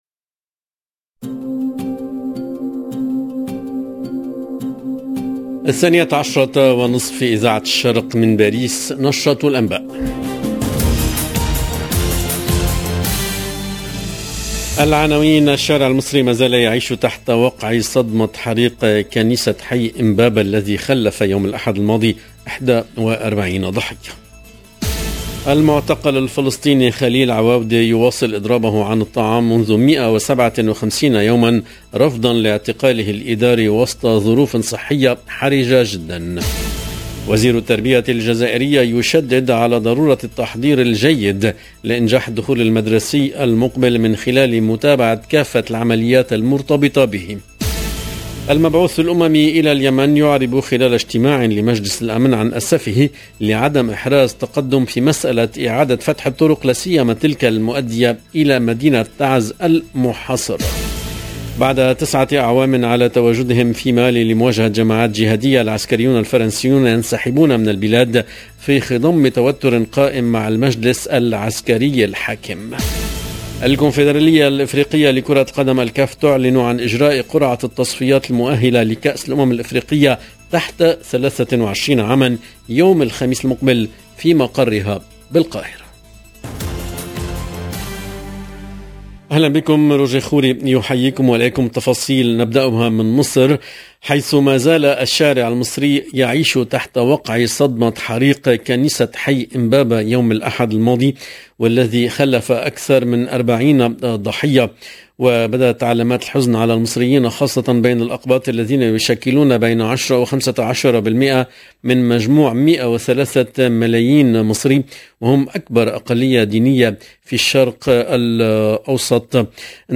LE JOURNAL DE 12H30 EN LANGUE ARABE DU 16/8/2022